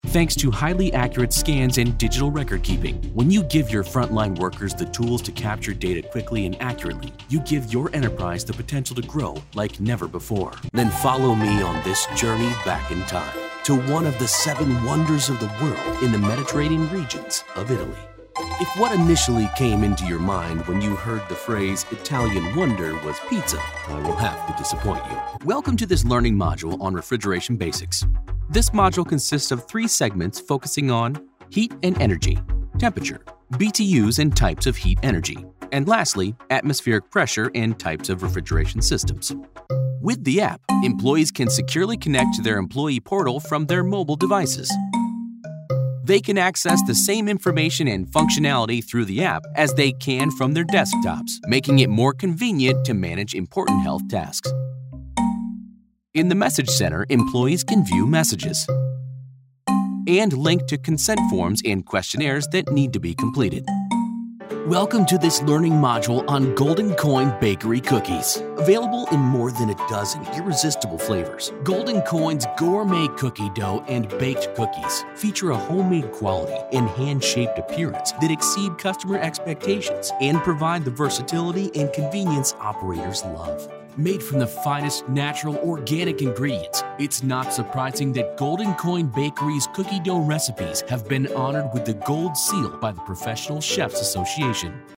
Male Voice actor with great range, variety and tone. Naturally comedic and warm and can deliver nearly any message on point.
englisch (us)
Sprechprobe: eLearning (Muttersprache):